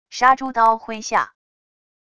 杀猪刀挥下wav音频